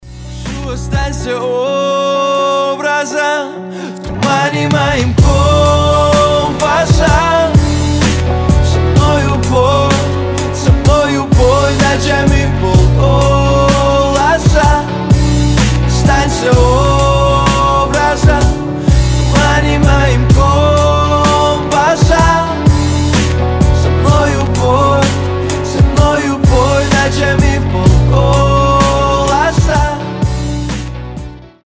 • Качество: 320, Stereo
красивые
лирика
медленные